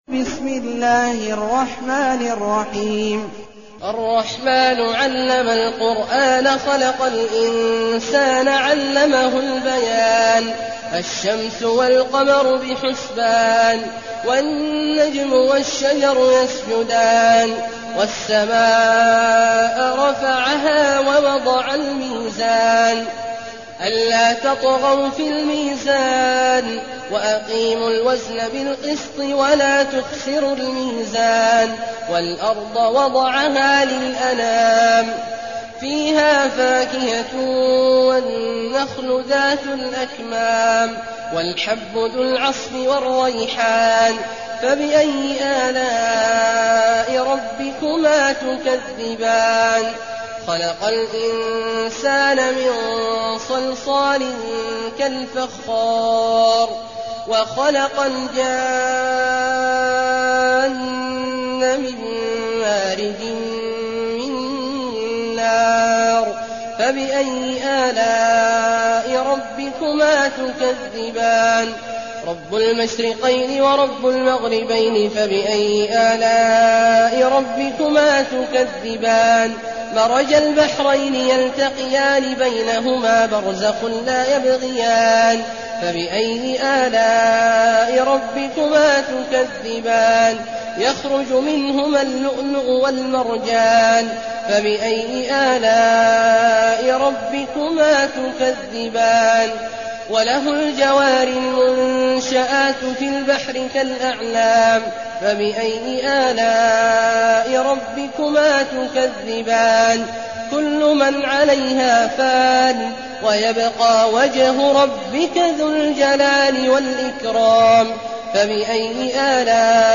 المكان: المسجد النبوي الشيخ: فضيلة الشيخ عبدالله الجهني فضيلة الشيخ عبدالله الجهني الرحمن